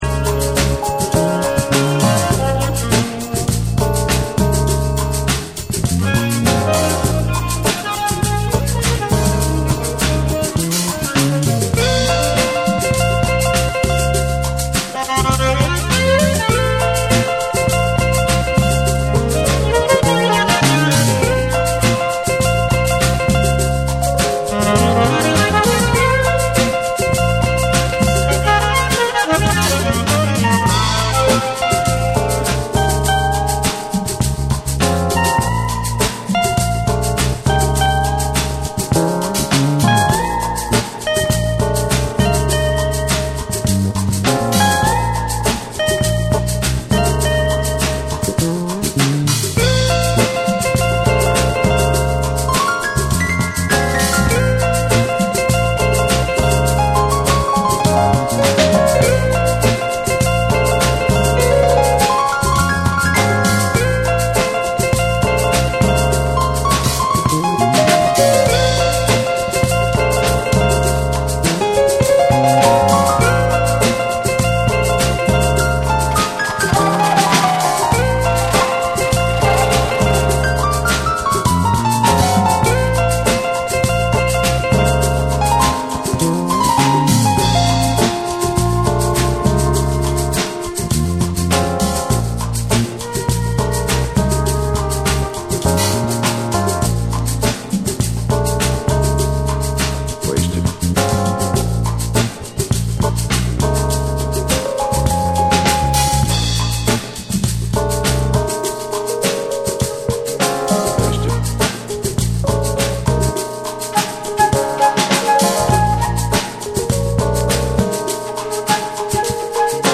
BREAKBEATS